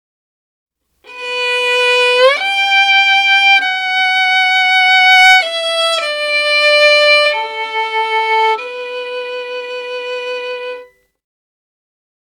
Saddest_Violin_5
cinema famous film funny hearts-and-flowers motif movie sad sound effect free sound royalty free Movies & TV